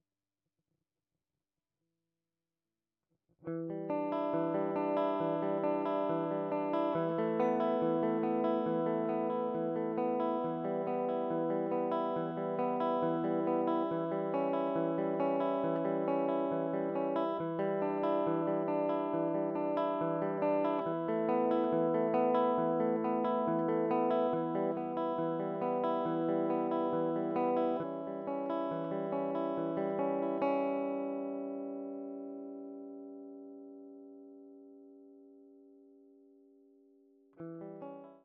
benötige einfache Intro Passge Plectrum-Picking